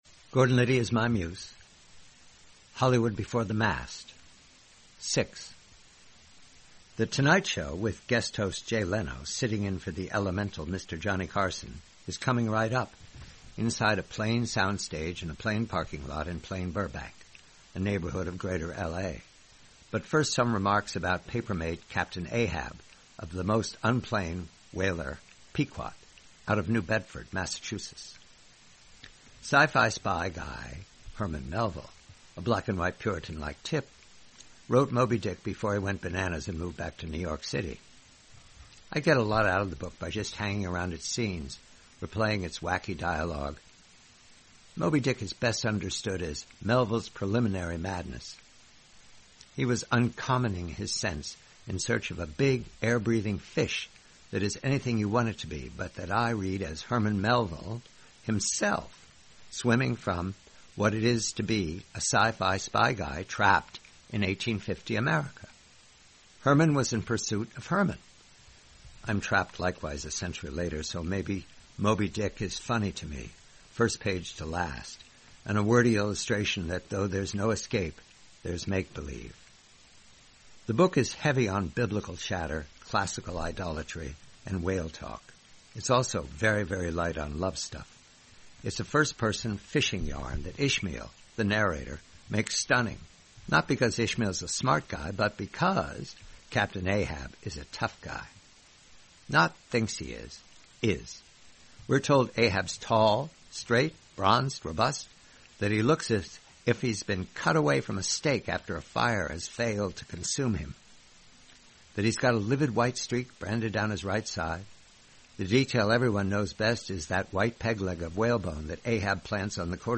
Read by the host.